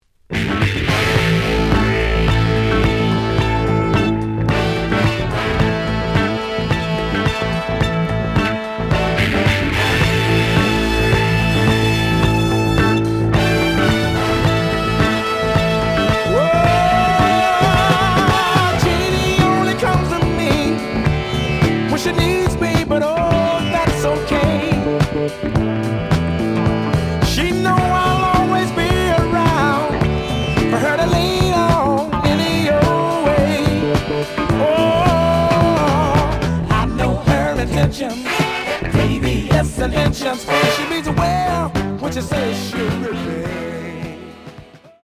The audio sample is recorded from the actual item.
●Genre: Soul, 70's Soul
Slight cloudy on both sides.